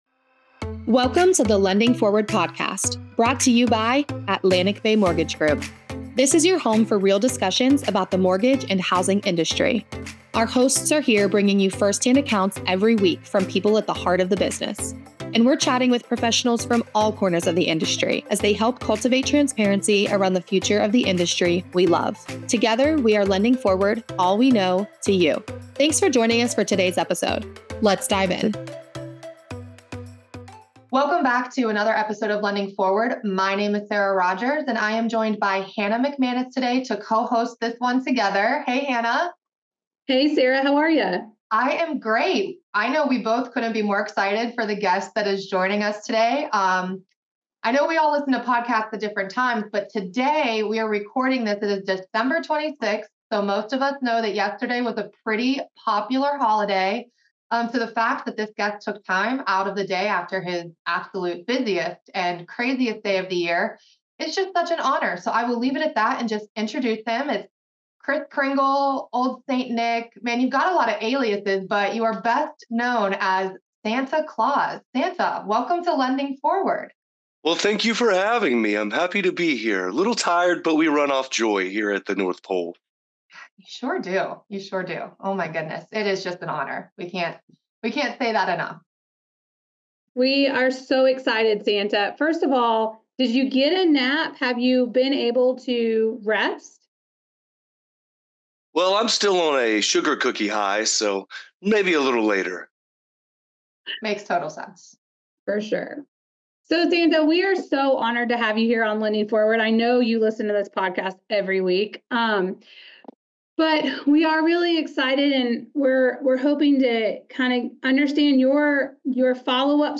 The big man himself, Santa, is joining us for a chat the day after Christmas to drop his top business secrets on how he gets it done every year! At the end of the day, he's running a business just like us in the housing industry, so his tips on productivity, follow-up, and staying up to date on trends will resonate with us all.